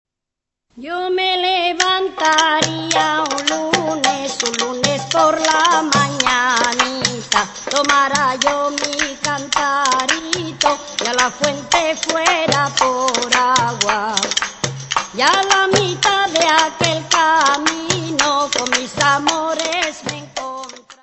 Cantares y romances tradicionales sefardies de Marruecos : el ciclo vital y el ciclo festivo : romances con referente épico histórico hispánico : romances sobre adúlteras y presos : romances sobre adúlteras = Traditional sephardic songs and ballads from Morocco
Notas:  Todas as gravações foram recolhidas em trabalho de campo realizado em Israel; Trablho de campo decorrido de 1979 a 1990; No do Serviço de Aquisições e Tratamento Técnico